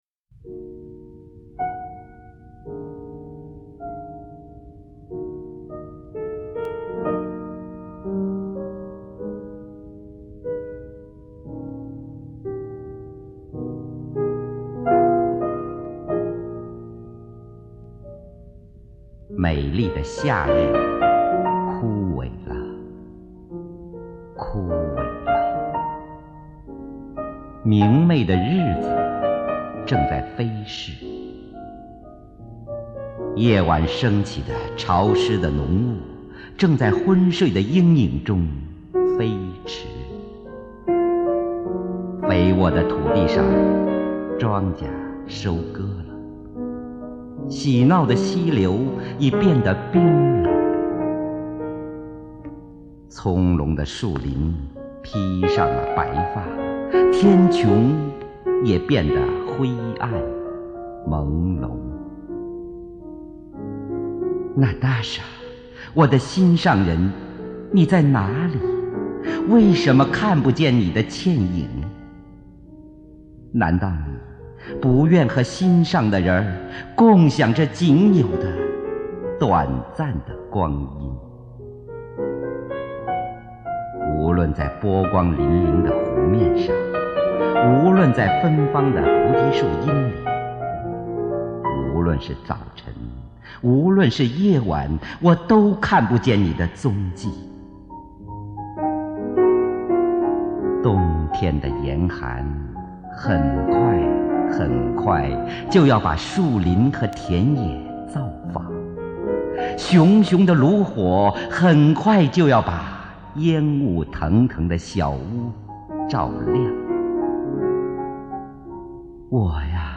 普希金诗配乐朗诵
（由录音带转录）